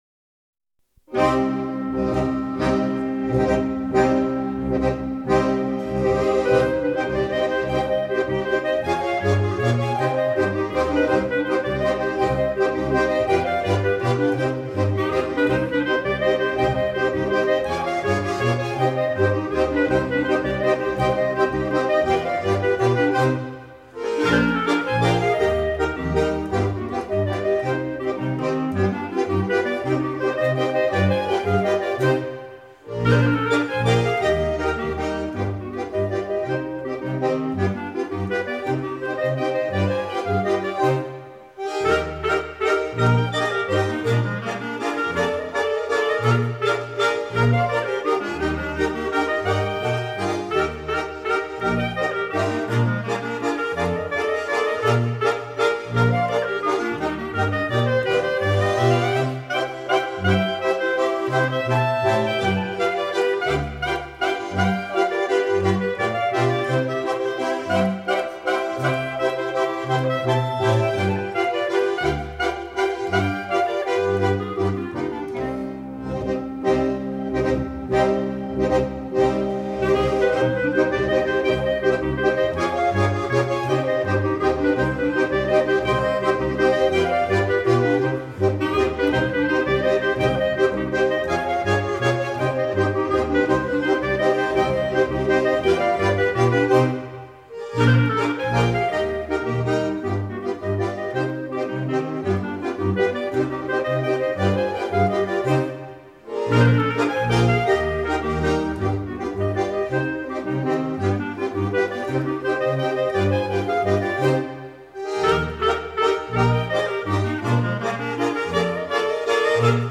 Les Muverans: La Choche-Vieille (Mazurka-Valse)
Western Switzerland.